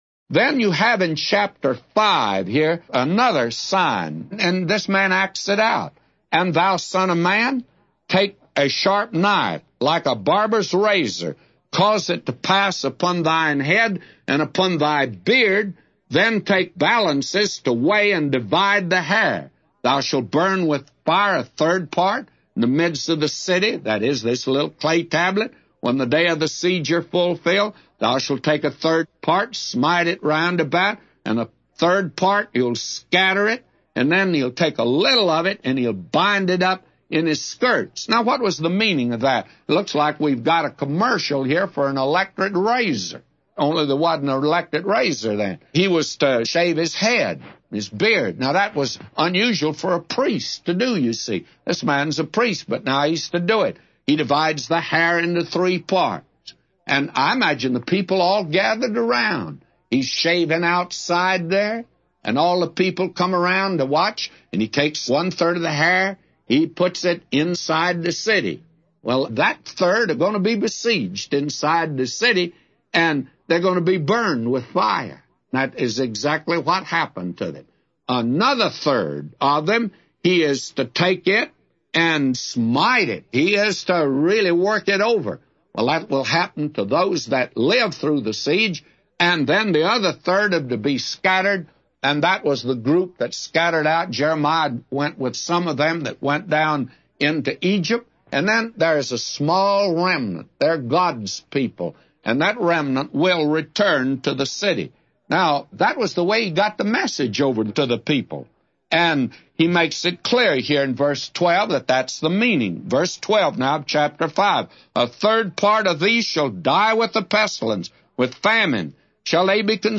A Commentary By J Vernon MCgee For Ezekiel 5:1-999